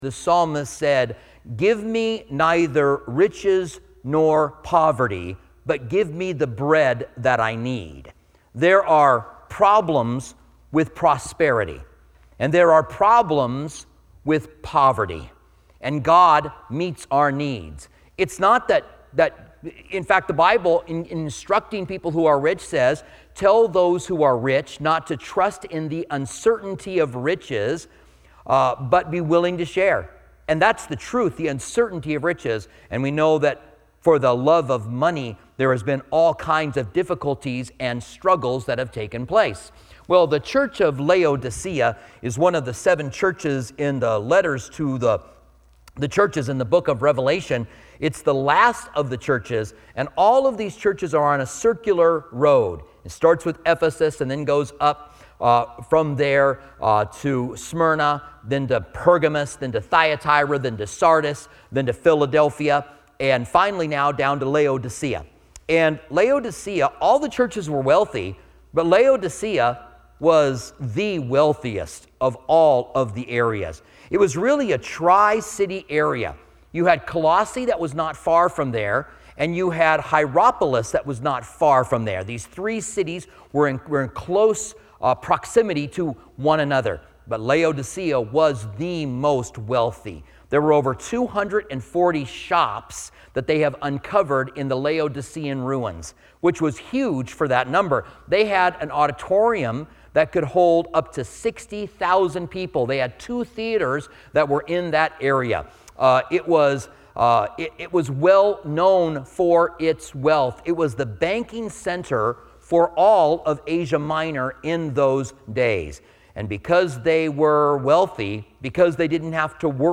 preaching on the lukewarm church of Laodicea in Revelation 3:14-22